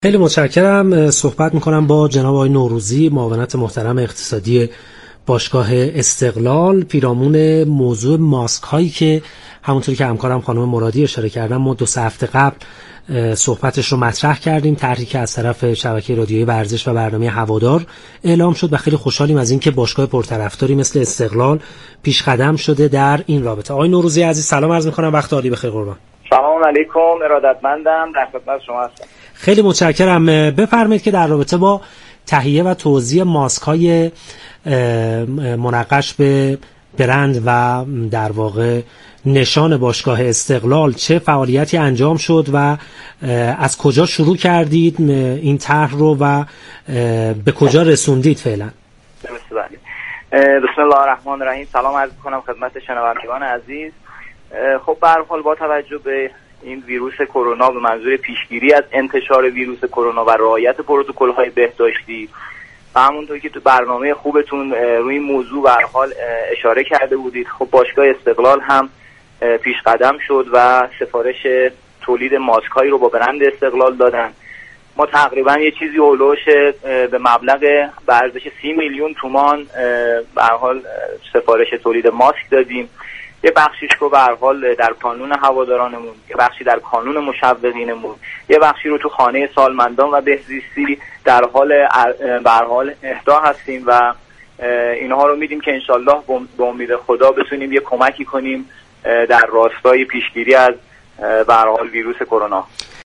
در گفتگو با رادیو ورزش